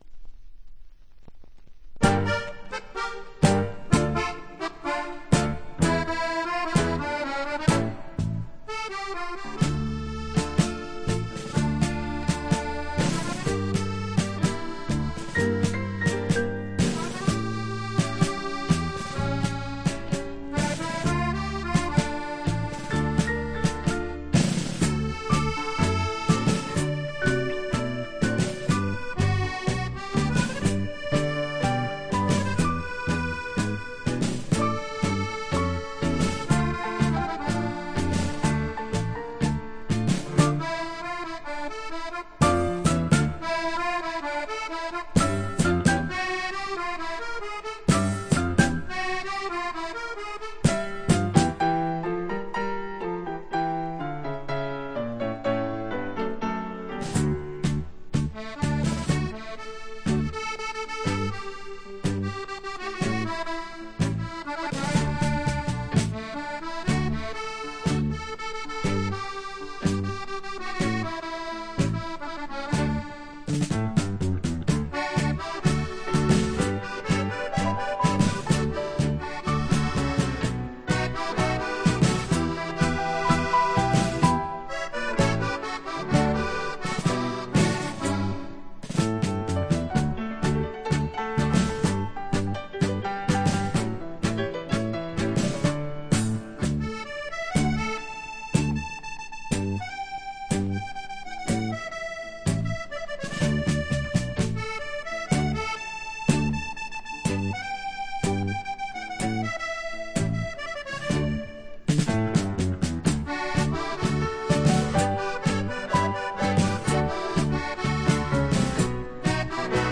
Жанр: Easy Listening, Accordion